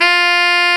SAX TENORF0T.wav